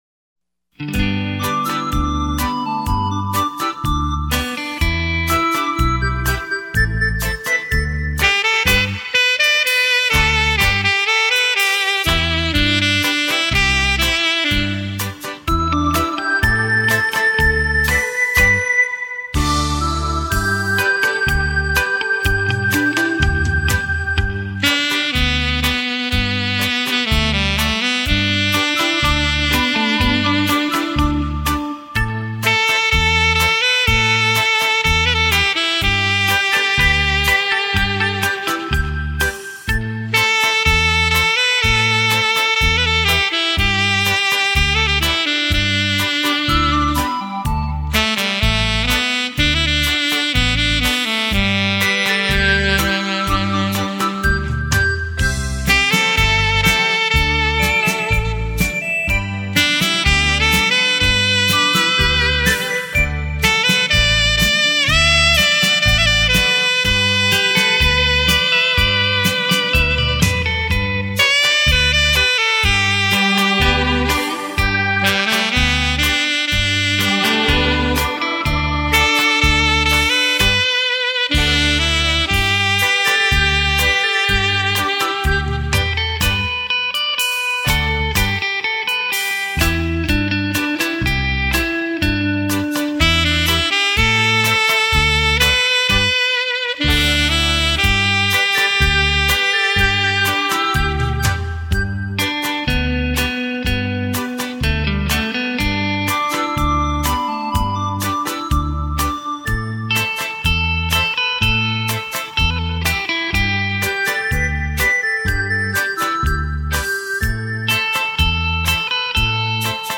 畅销东洋演歌名曲演奏